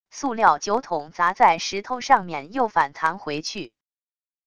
塑料酒桶砸在石头上面又反弹回去wav音频